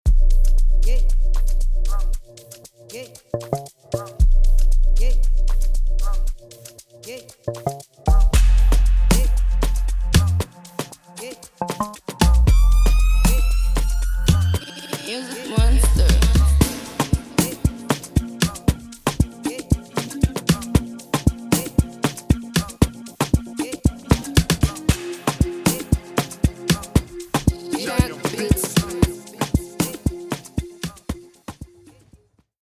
Tempo: 116
Genre: Afrobeats, Afropop, Afro-rave